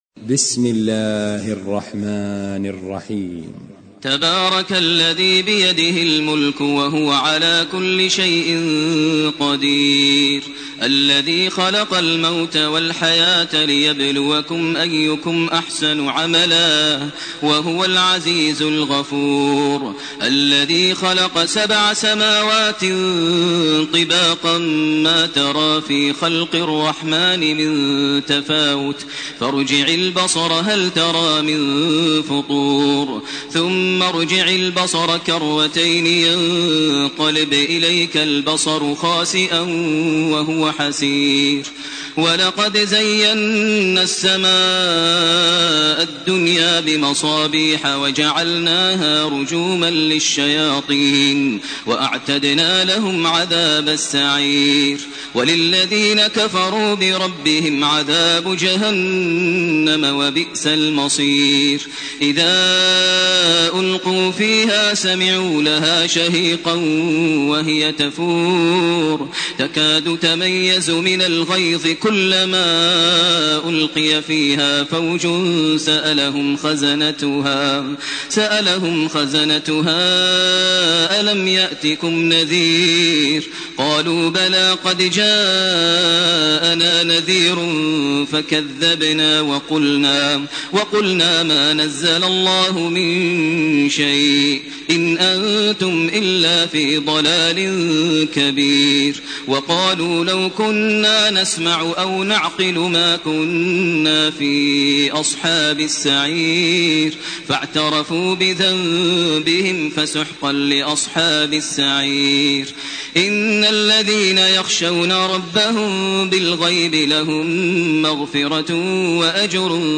سورة الملك سورة القلم سورة الحاقة سورة المعارج سورة نوح > تراويح ١٤٢٨ > التراويح - تلاوات ماهر المعيقلي